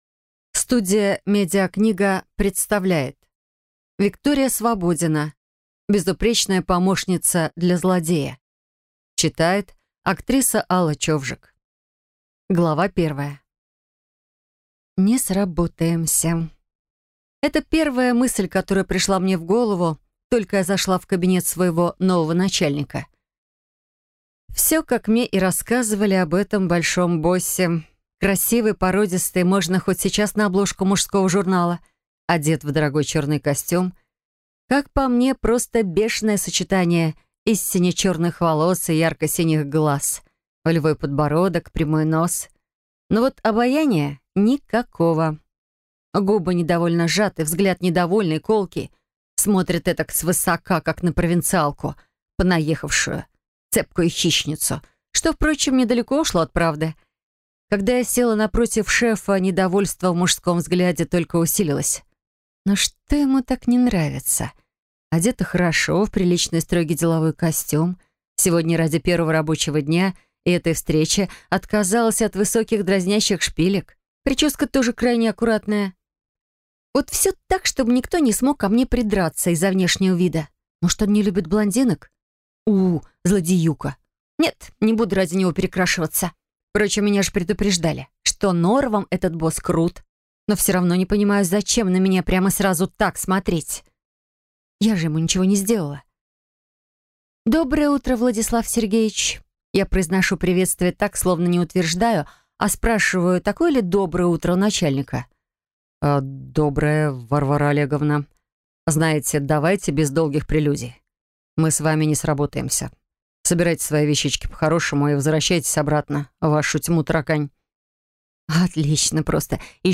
Аудиокнига Безупречная помощница для злодея | Библиотека аудиокниг